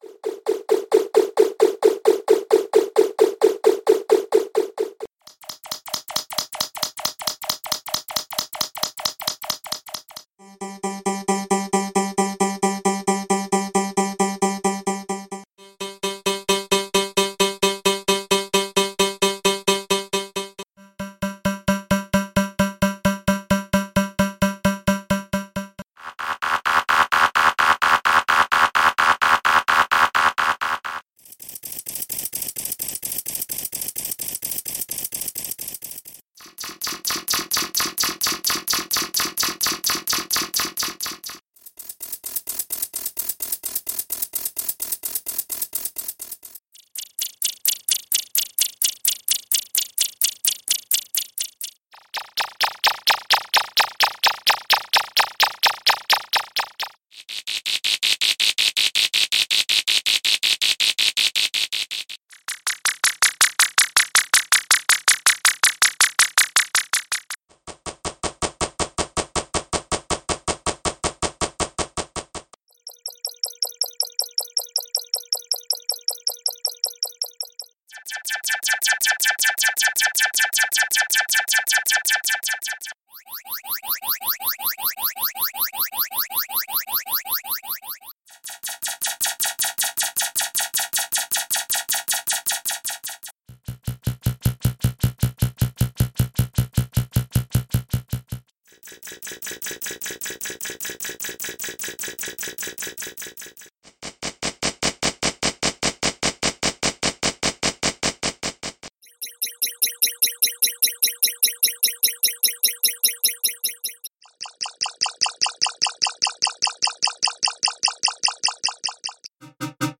Sound Effects - SIGNALS - V9 - p2
Signals Actual Length: 1 Minute (60 Sec) Each Sound.